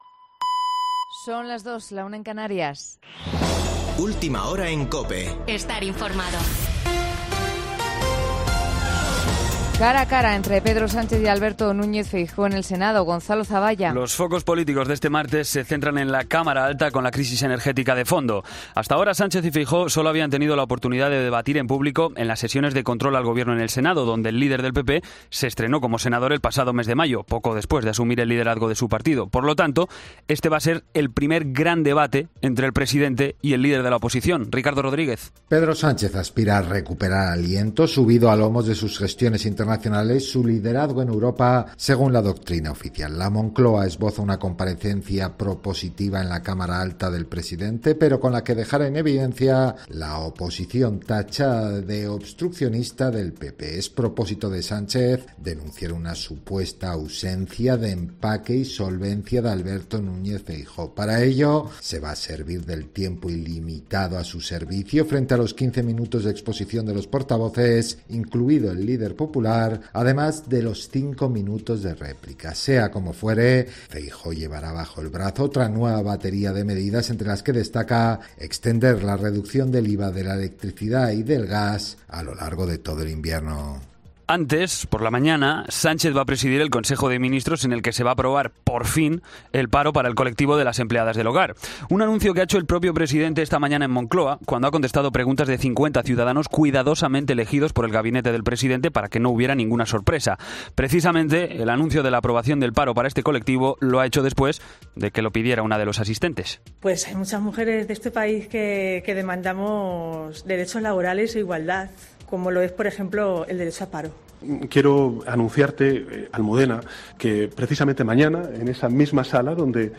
Boletin de noticias COPE del 6 de septiembre a las 02:00 horas